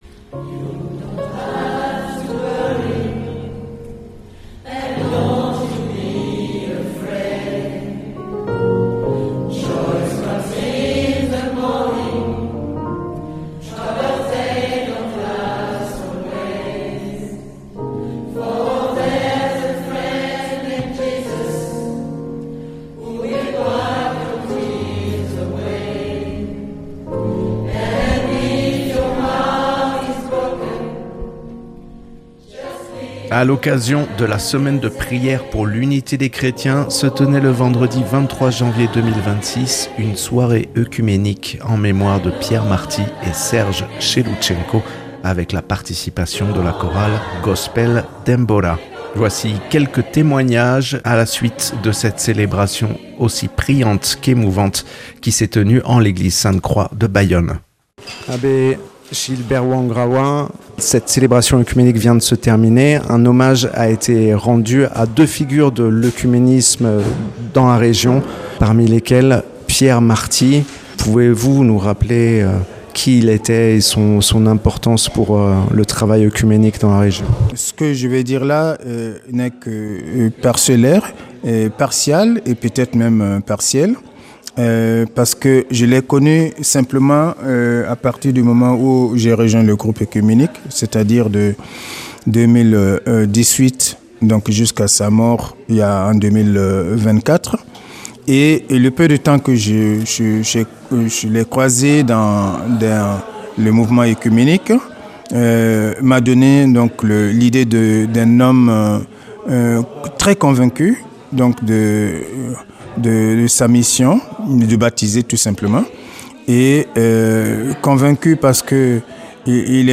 Un reportage réalisé à l’issue de cette célébration à l’initiative du groupe œcuménique et la participation de la chorale Gospel Denbora.